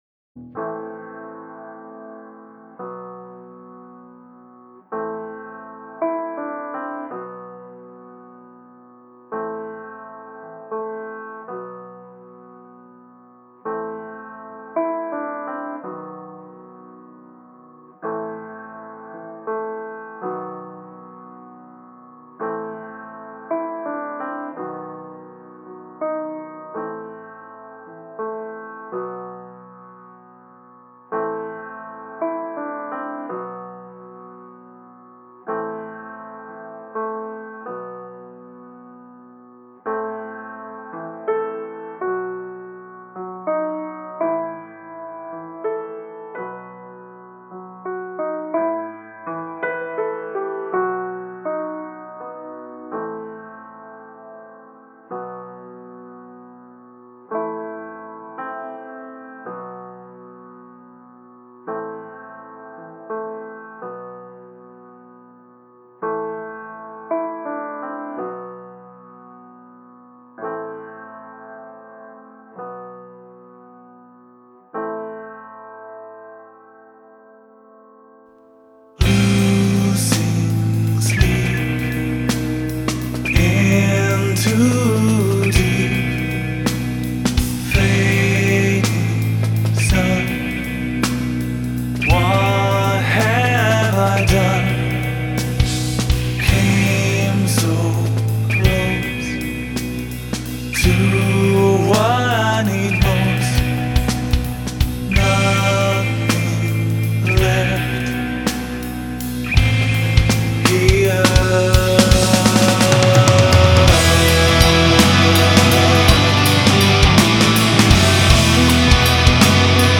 progressive metal